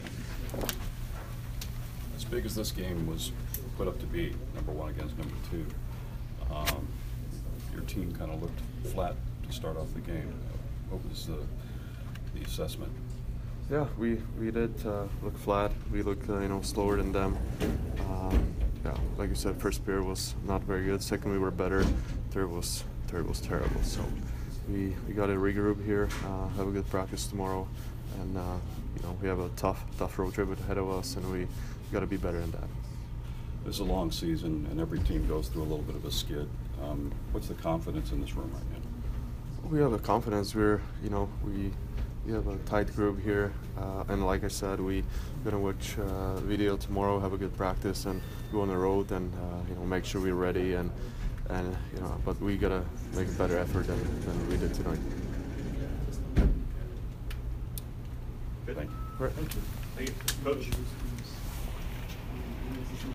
Ondrej Palat Post-Game 1/18